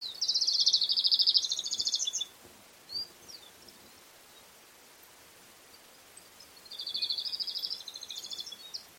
Pampas Pipit (Anthus chacoensis)
Ejemplares que se encontraban en un cultivo de soja, mezclado con rastrojo de trigo.
Partido de Lobos, Provincia de Buenos Aires.
Detailed location: Campo cercano a la laguna Culú Culú
Condition: Wild
Certainty: Observed, Recorded vocal